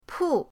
pu4.mp3